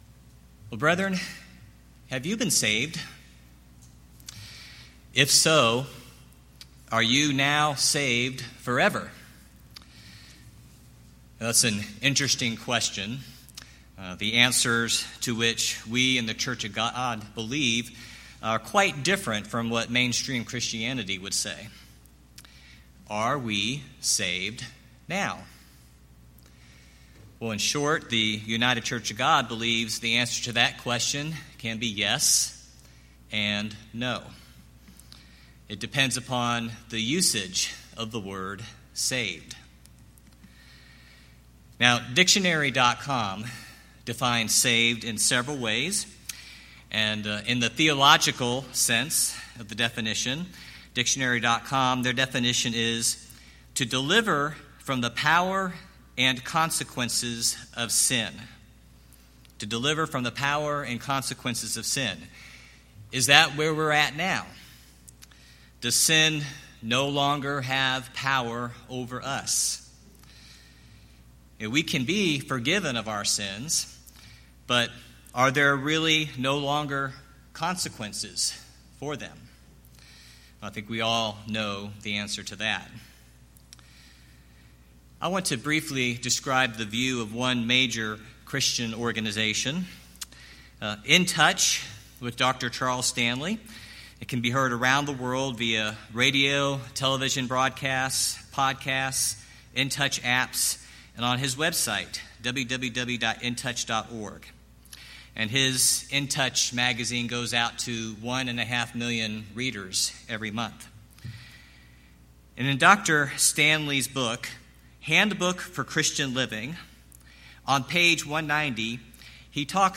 Sermons
Given in East Texas